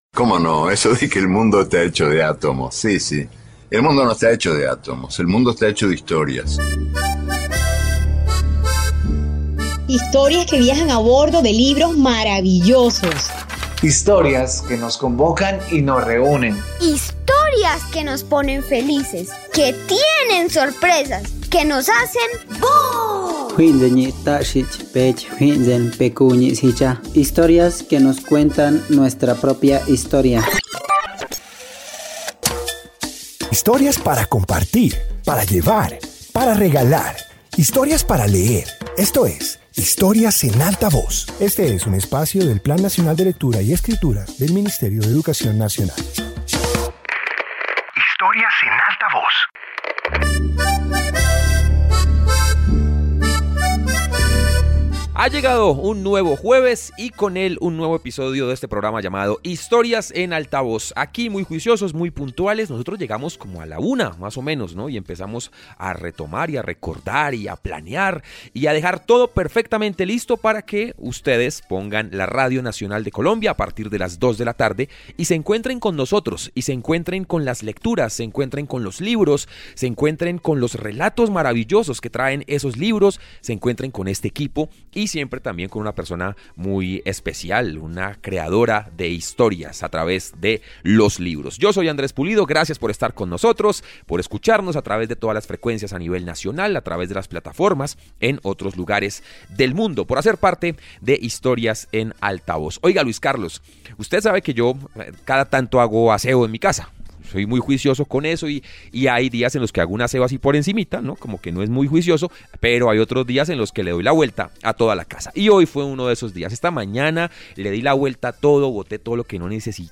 Introducción Este episodio de radio presenta narraciones sobre detectives, pistas y misterios. Comparte historias que muestran cómo se resuelven diferentes enigmas a través de la investigación.